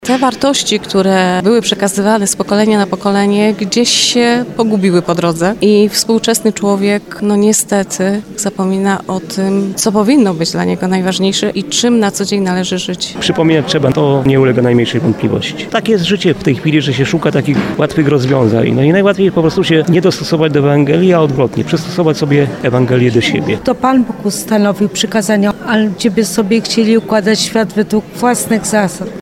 3sonda_wyklady-1.mp3